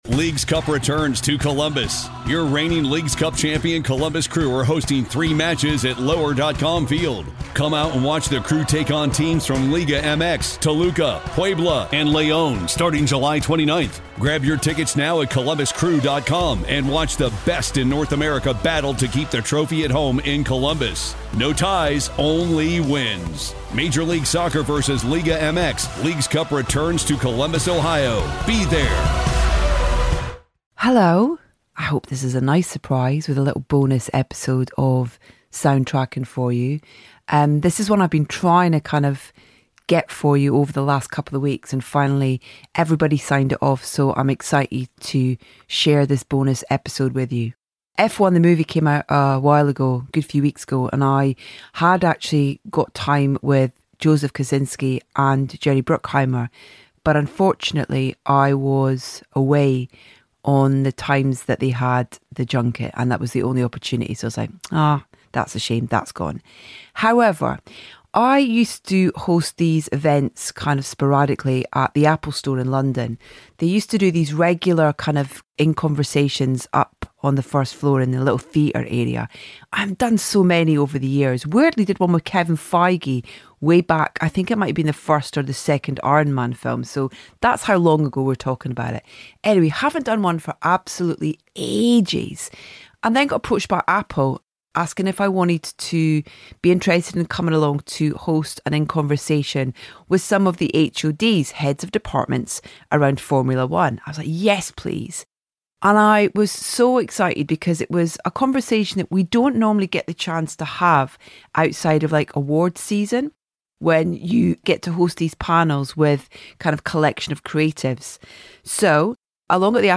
We have another bonus episode of Soundtracking for you, recorded in front of an audience at Apple Regent Street (London). The subject of this genuinely fascinating conversation is all about the recent release of F1® The Movie, and features key members of the production team.